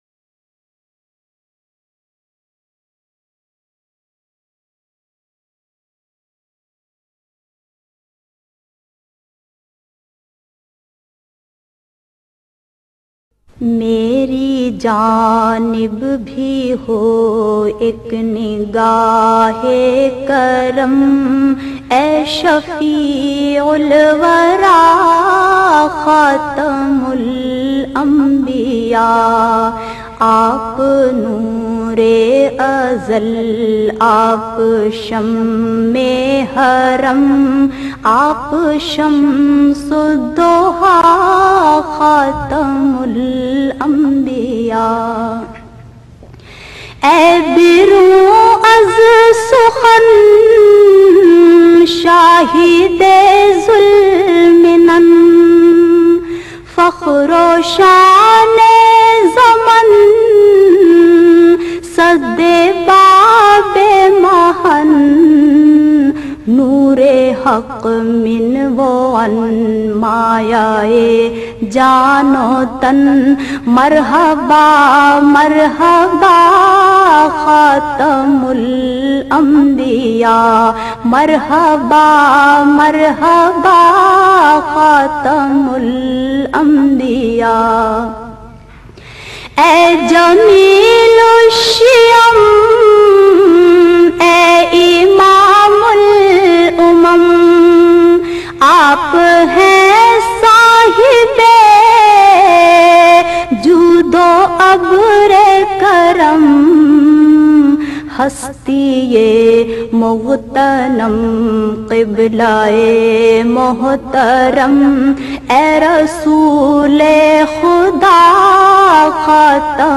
She has her very own style of reciting Naats.